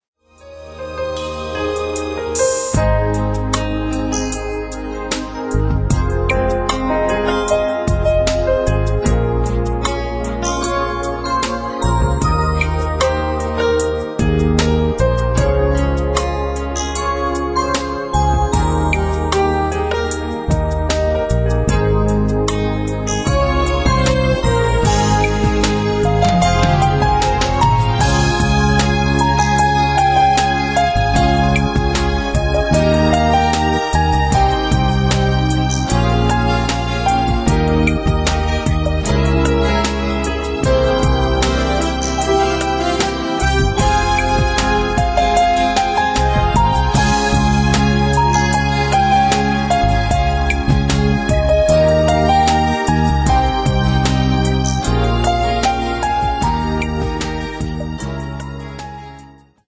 Nhạc không lời